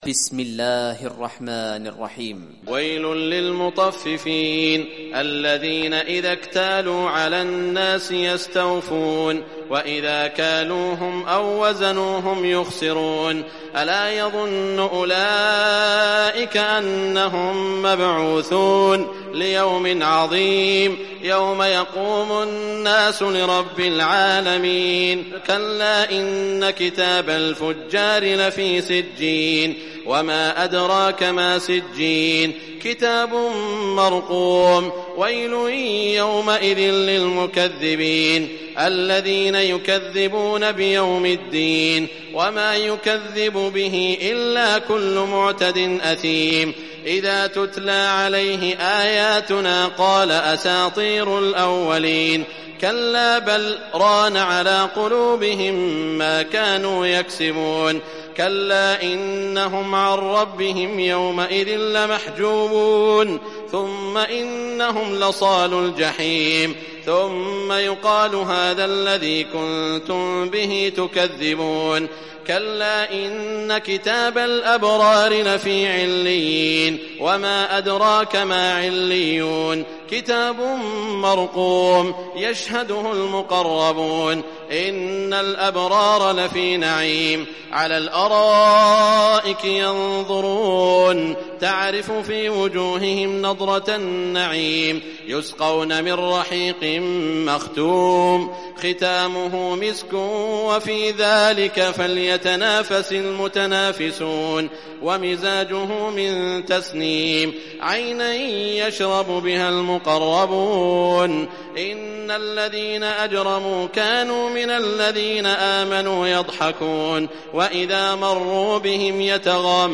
Surah Mutaffifin Recitation by Sheikh Shuraim
Surah Mutaffifin, listen or play online mp3 tilawat / recitation in Arabic in the beautiful voice of Sheikh Shuraim.